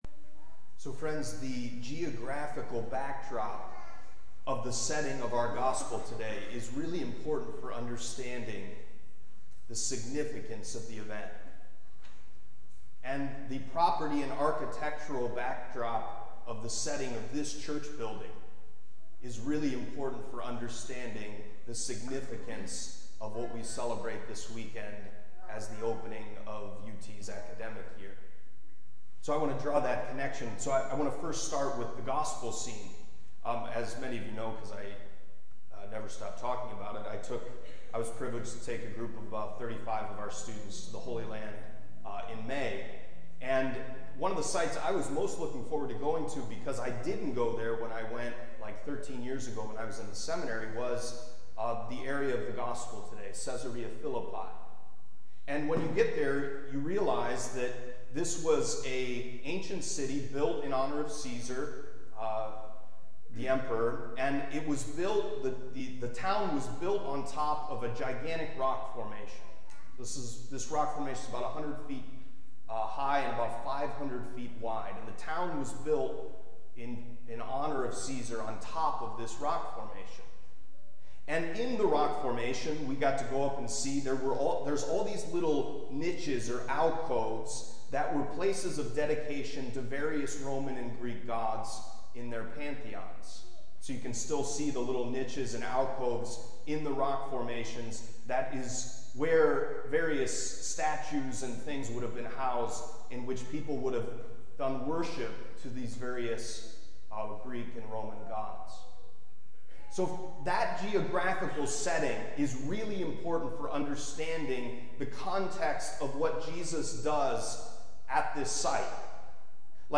Homily from the 21st Sunday in Ordinary Time, the weekend of August 28, 2023.